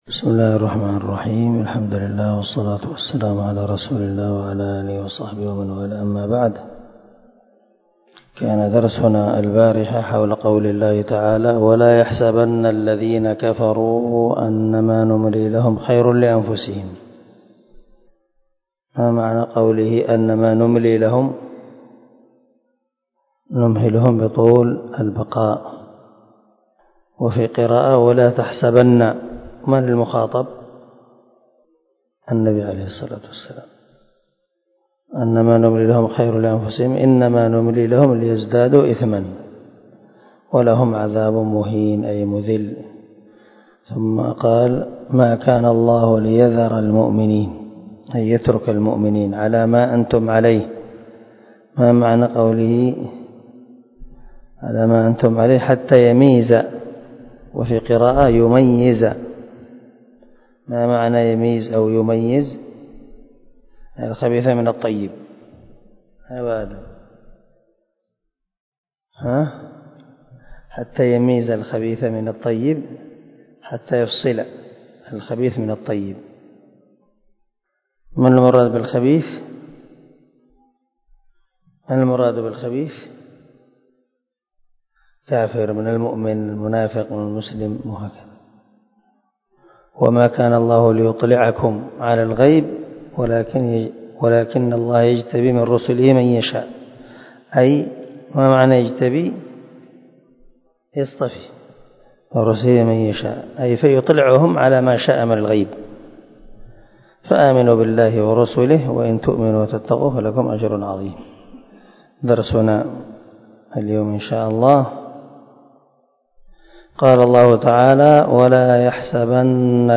222الدرس 67 تفسير آية ( 180 ) من سورة آل عمران من تفسير القران الكريم مع قراءة لتفسير السعدي